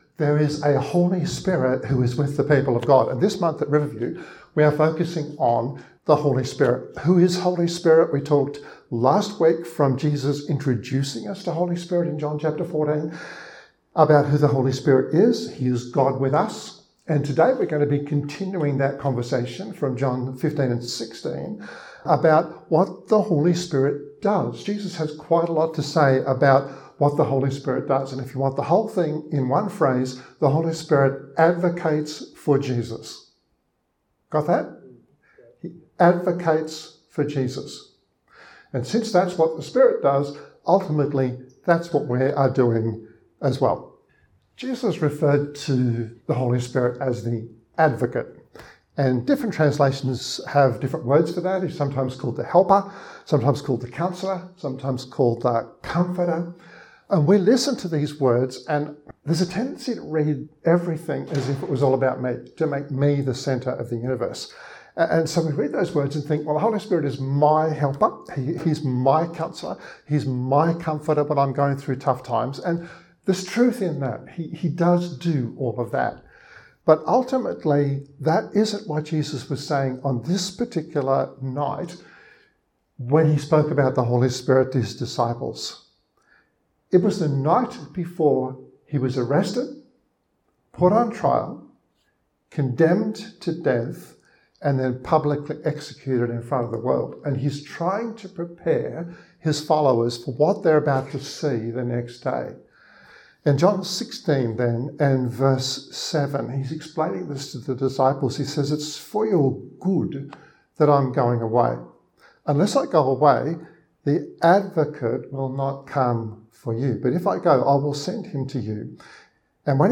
This 27-minute podcast was recorded at Riverview Joondalup, 26 September 2021: